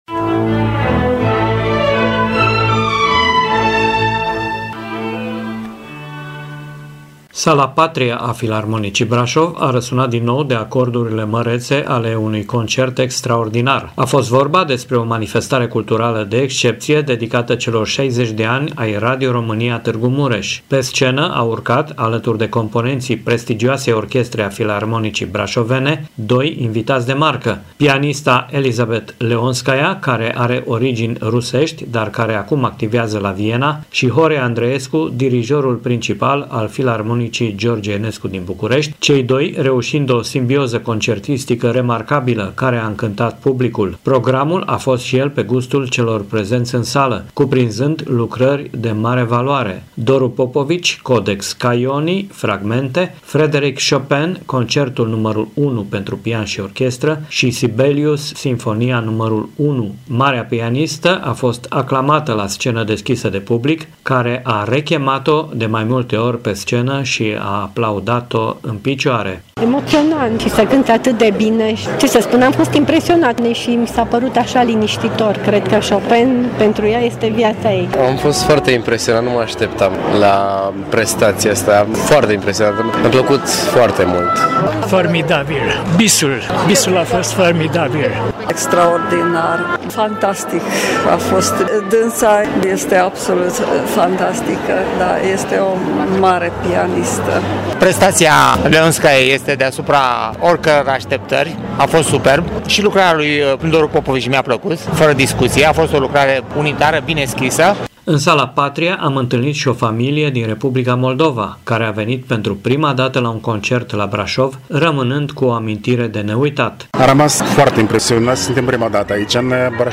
reportaj.mp3